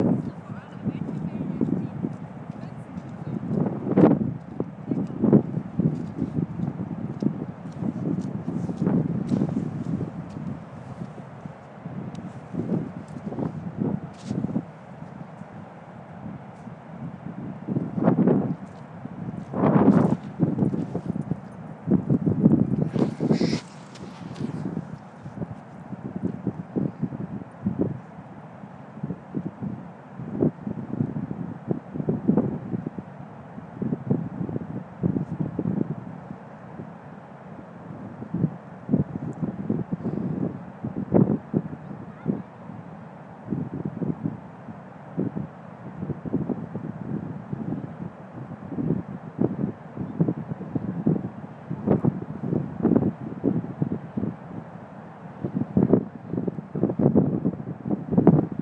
Wintermeeresrauschen
ocean, sound, winter, denmark